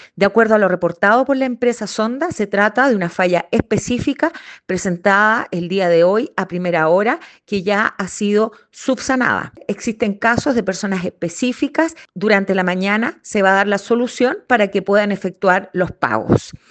Esto fue confirmado por la directora del Transporte Público Metropolitano, Paola Tapia, quien señaló que todo ya fue subsanado.